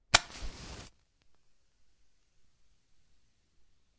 描述：打一场比赛：匹配轻，然后燃烧噪音。
Tag: 匹配 火焰 照明 火柴盒 火柴 撞击 OWI